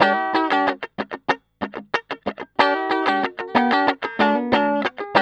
TELEDUAL G#3.wav